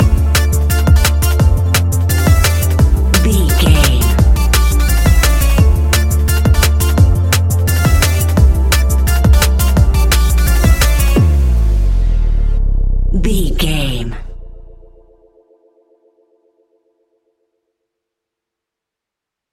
Ionian/Major
techno
trance
synthesizer
synthwave
instrumentals